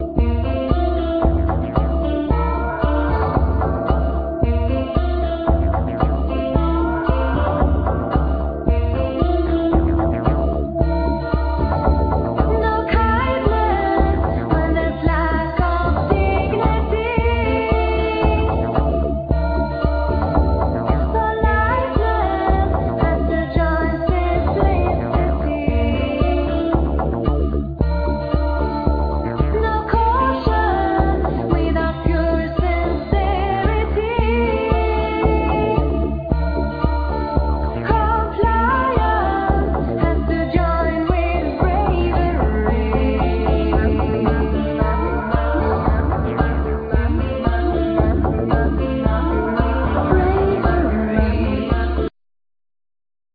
Vocal, Keyboards, Piano
Keyboards, Programming, Piano, TR-808
Bouzouki, Mandlin, Programming, Bass, Guitar, Vocal
Flugelhorn, Trumpet
Guitar, Drums
Violin, Viola, String arrangement
Cello